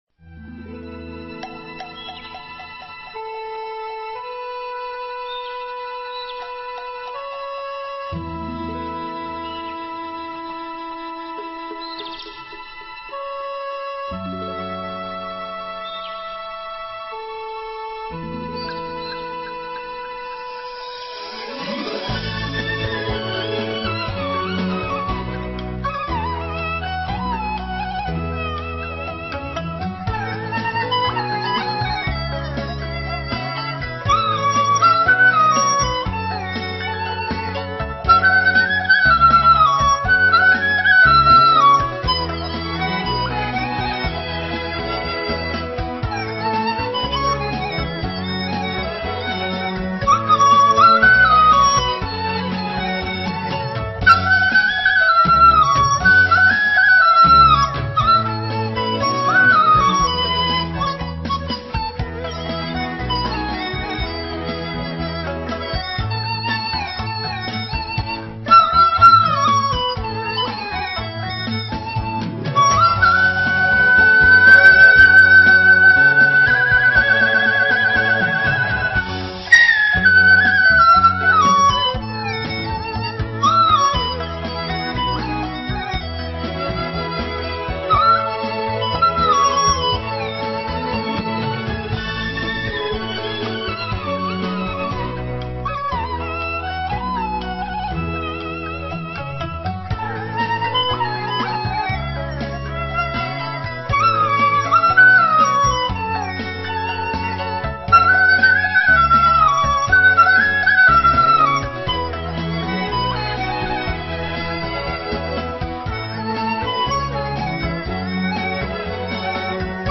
笛子曲